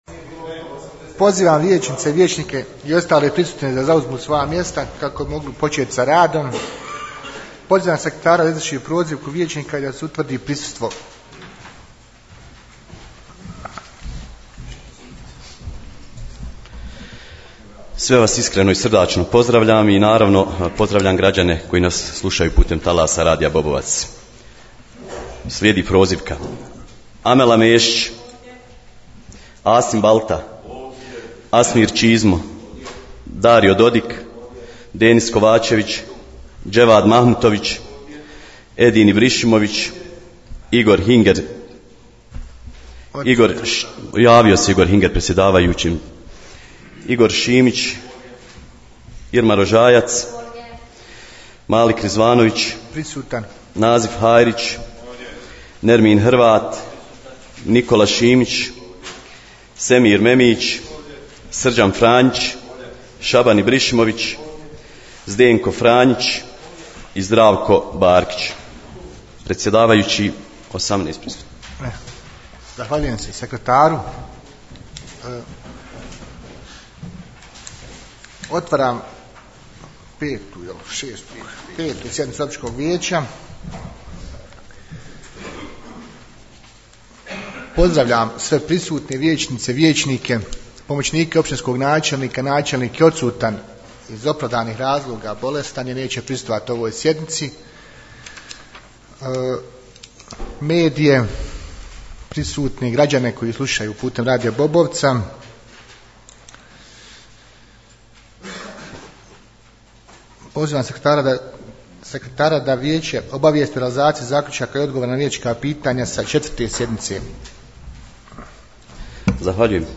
U četvrtak, 27.03.2025. godine održana je 5. sjednica Općinskog vijeća Vareš, poslušajte tonski zapis .....